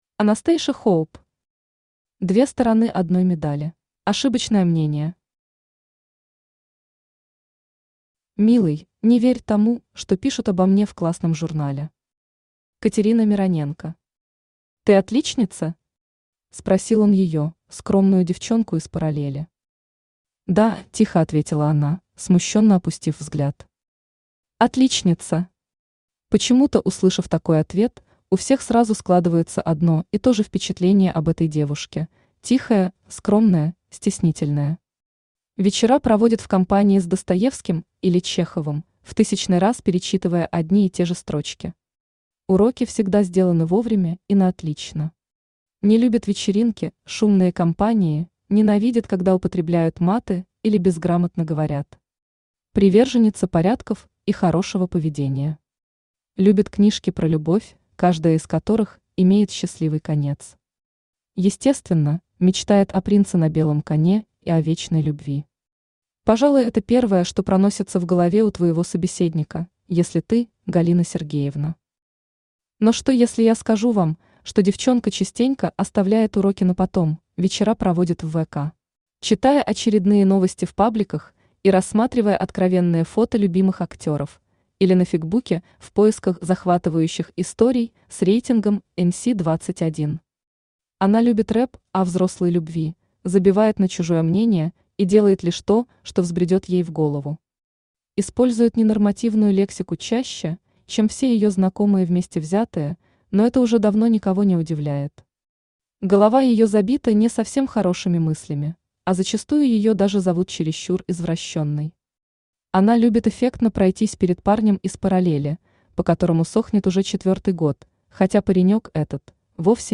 Аудиокнига Две стороны одной медали | Библиотека аудиокниг
Aудиокнига Две стороны одной медали Автор Анастейша Хоуп Читает аудиокнигу Авточтец ЛитРес.